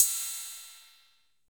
Index of /90_sSampleCDs/Roland L-CD701/DRM_Drum Machine/KIT_CR-78 Kit
CYM 606 CY0I.wav